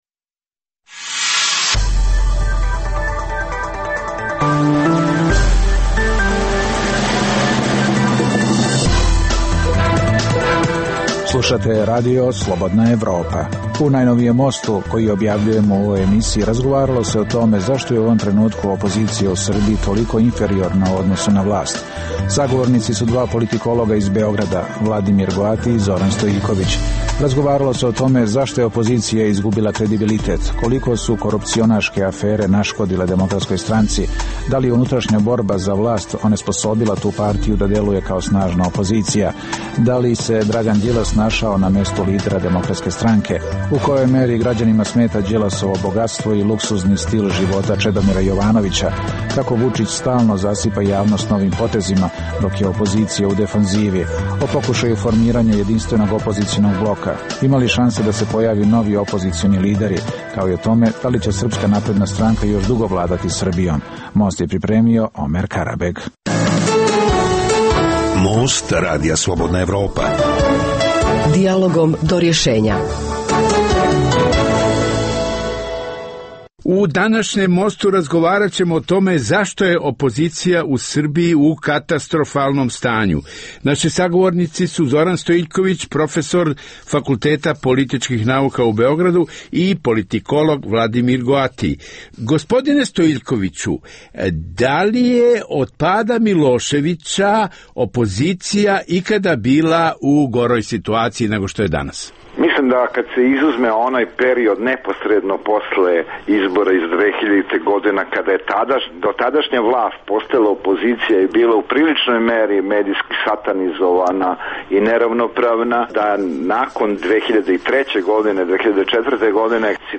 u kojem ugledni sagovornici iz regiona razmtraju aktuelne teme. U Mostu koji objavljujemo u ovoj emisiji, razgovaralo o tome zašto je u ovom trenutku opozicija u Srbiji toliko inferiorna u odnosu na vlast.